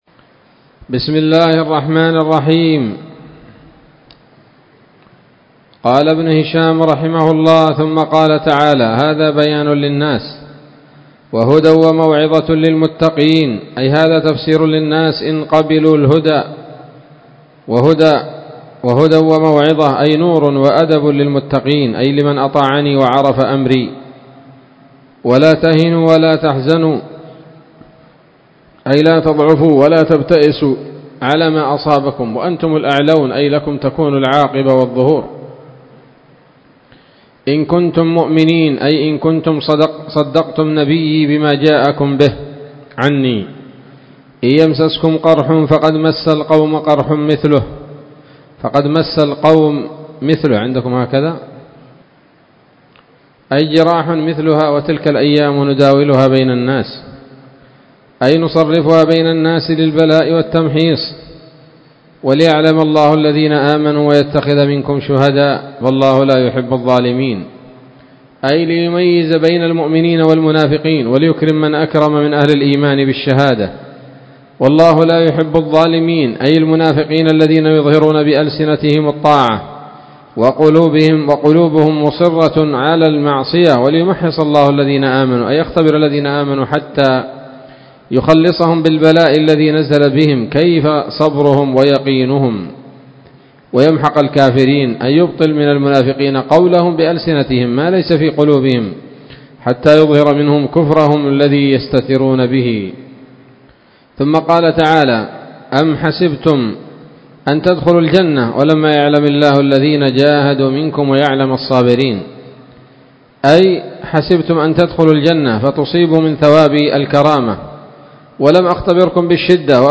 الدرس السبعون بعد المائة من التعليق على كتاب السيرة النبوية لابن هشام